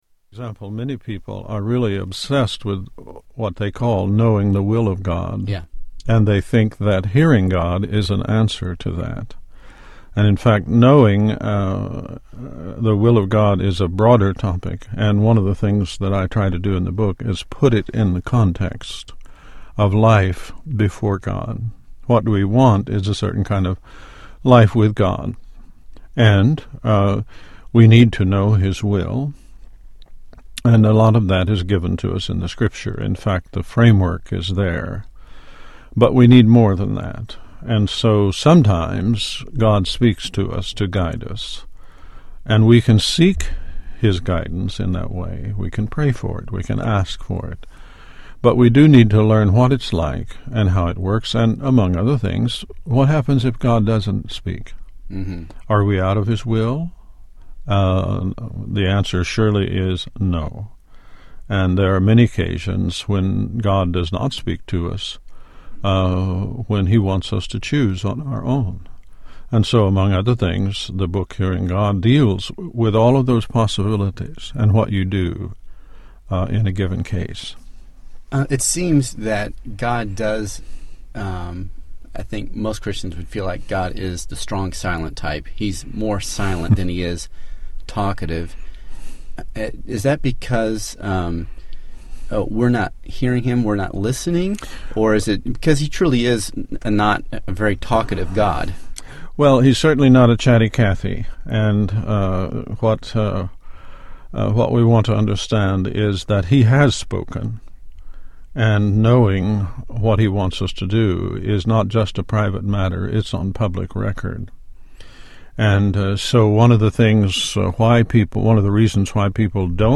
Tags: Media Writer Christian Christian audio books Audio books